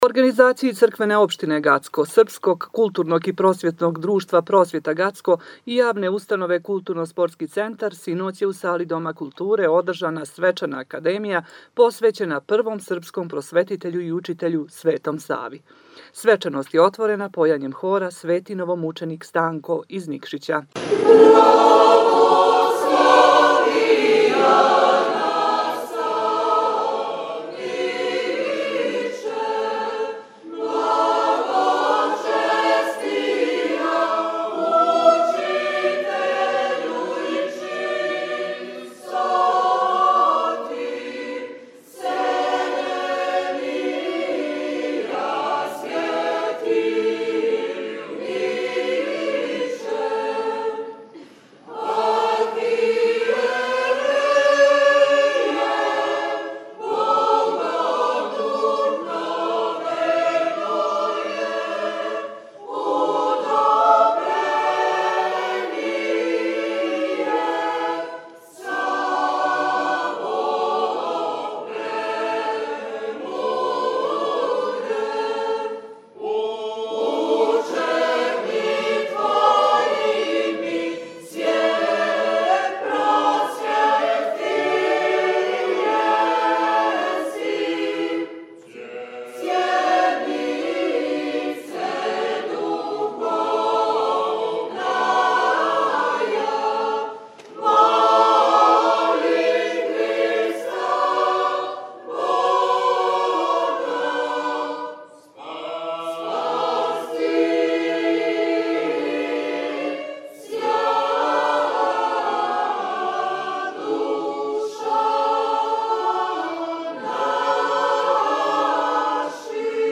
U organizaciji Crkvene opštine Gacko, SKPD „Prosvjeta“ Gacko i JU „Kulturno-sportski centar“ a pod pokroviteljstvom Opštine Gacko , sinoć je u sali Doma kulture održana svečana akademija posvećena prvom srpskom prosvetitelju i učitelju – Svetom Savi. Svečanost je otvorena pojanjem hora „Sveti novomučenik Stanko“ iz Nikšića.